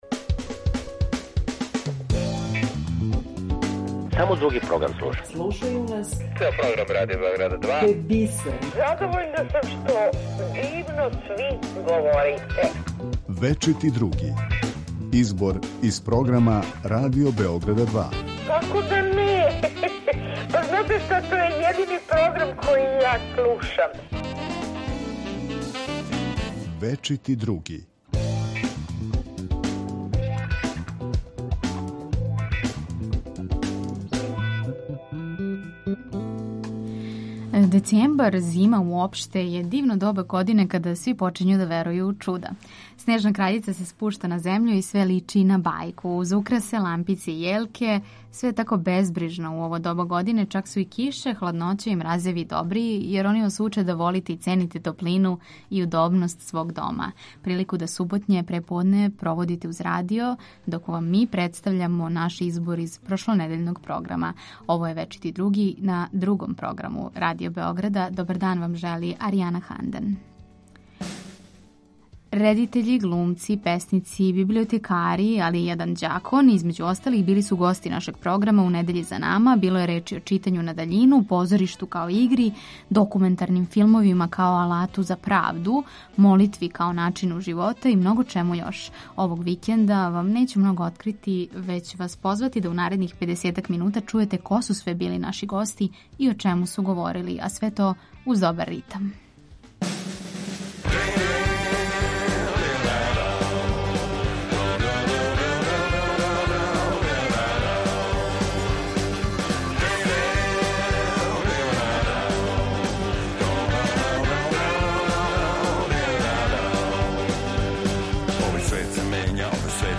У недељном избору из програма Радио Београда 2 за вас издвајамо делове из емисија: Клуб 2, Спорови у култури, У првих пет, Говори да бих те видео, Дигиталне иконе, Храм...
Поред тога што издвајамо делове из прошлонедељног програма, најавићемо и неке од емисија које ћете чути на Радио Београду 2.